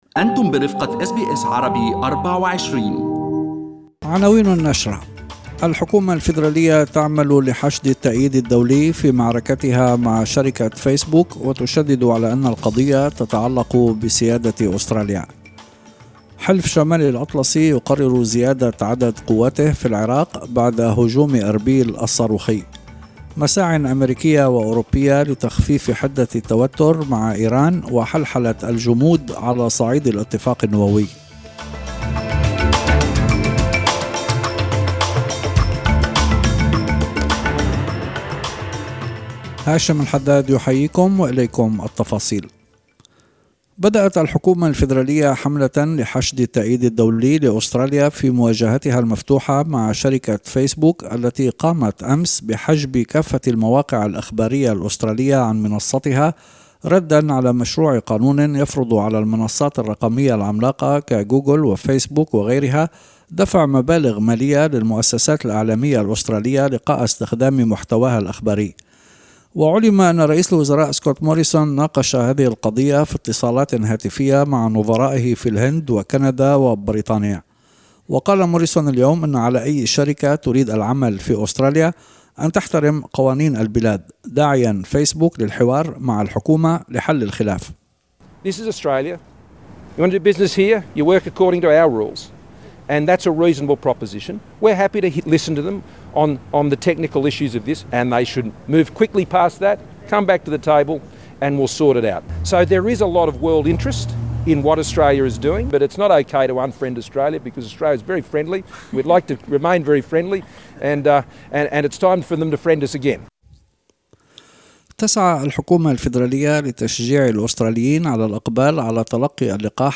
نشرة أخبار المساء 19/02/2021